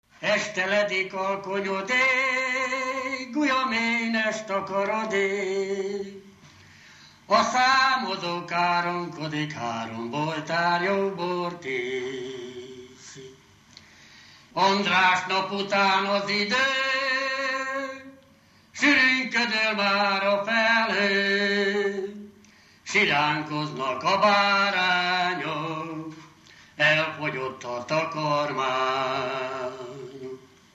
Felföld - Zemplén vm. - Bodrogvécs
ének
Stílus: 1.2. Ereszkedő pásztordalok
Kadencia: 8 (4) b3 1